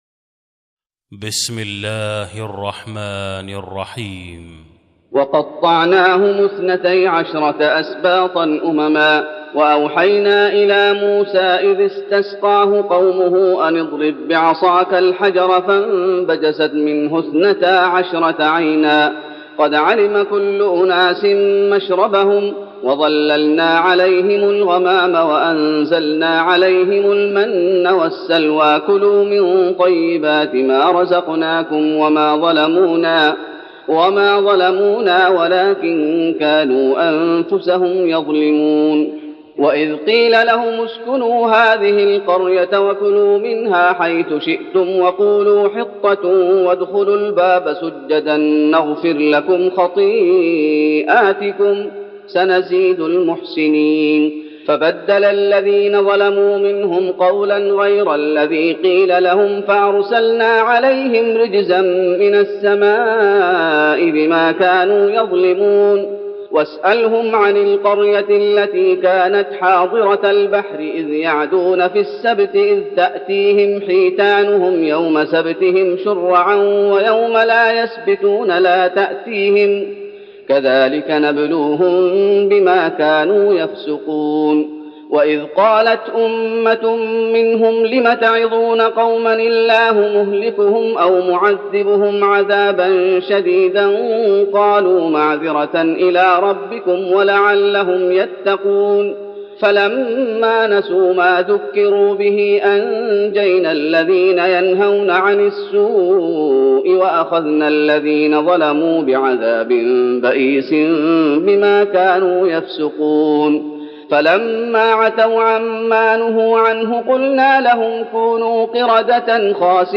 تراويح رمضان 1412هـ من سورة الأعراف (160-206) Taraweeh Ramadan 1412H from Surah Al-A’raf > تراويح الشيخ محمد أيوب بالنبوي 1412 🕌 > التراويح - تلاوات الحرمين